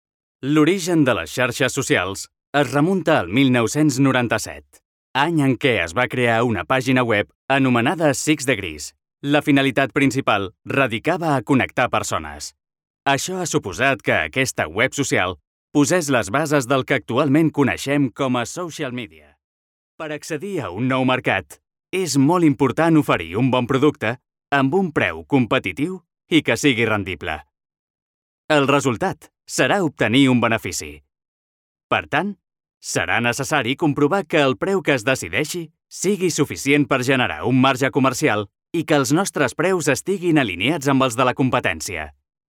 Jeune, Naturelle, Distinctive, Urbaine, Cool
E-learning